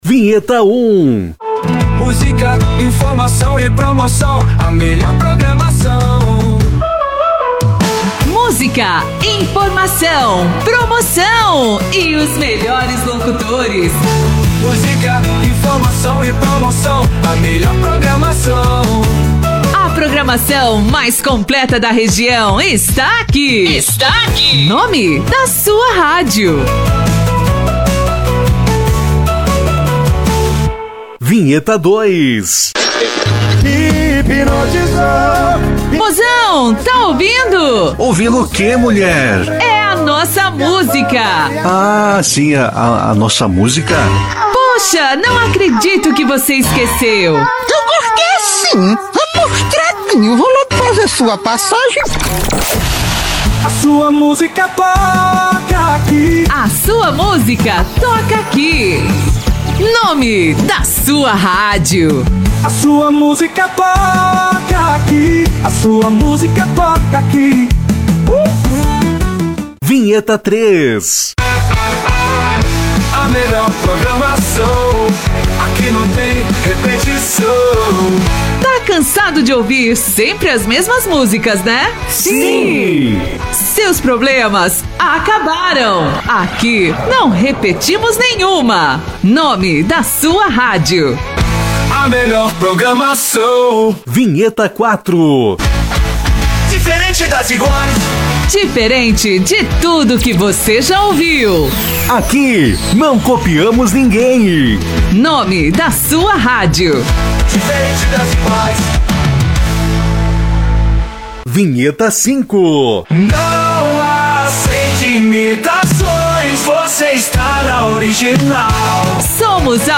13 vinhetas femininas pré-montadas com o nome da sua rádio
13 vinhetas carimbadas e personalizadas com o nome da sua rádio, todas com voz feminina!
– Nome da sua rádio gravado por uma locutora profissional
– O pacote é ideal para rádios de estilo jovem, pop e pop rock
– Vinhetas de alta qualidade, prontas para uso!